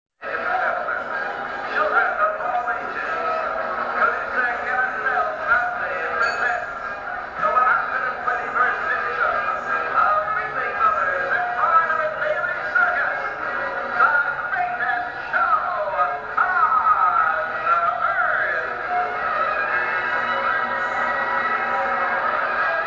* (Before the age of cell phones I brought my little micro-cassette recorder and made a crude but cool recording of the circus from inside the band I played with during the next to last show on Saturday.